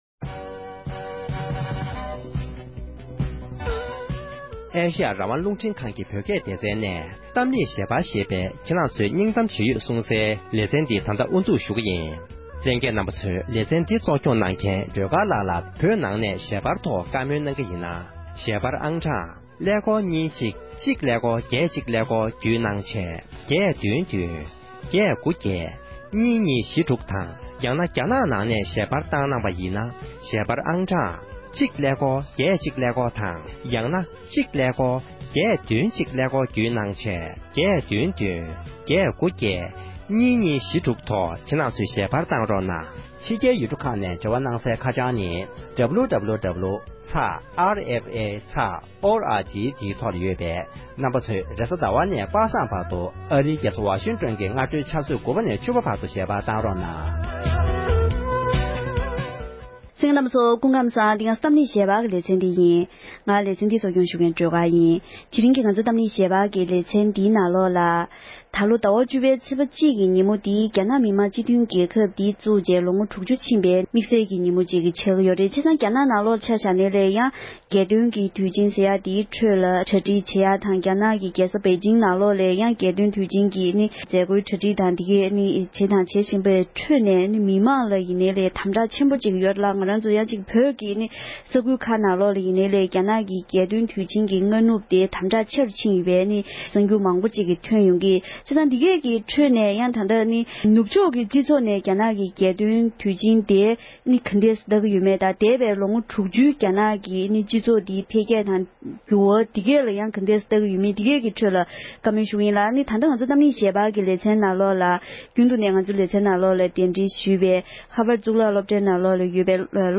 བཀའ་འདྲི